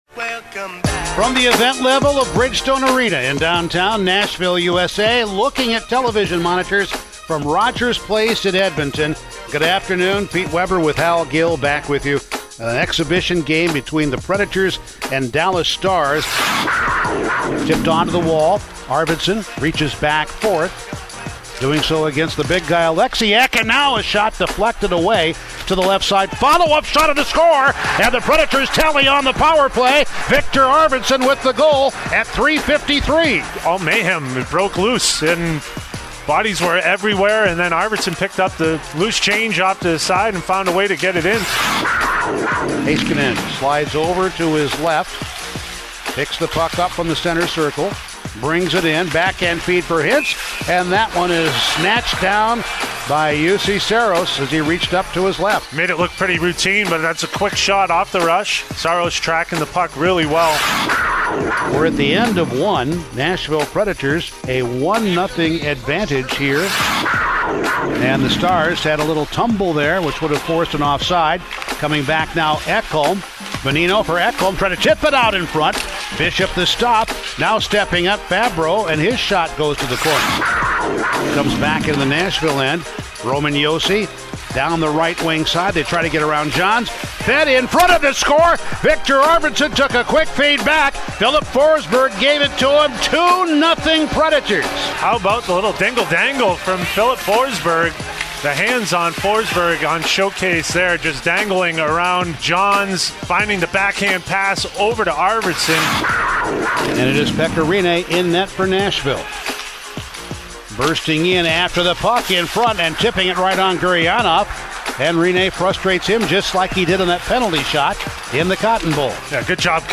Full radio highlights from the Nashville Predators' 2-0 win over Dallas in their first game back from the paused season and their only exhibition game before the hub city playoffs start on Sunday!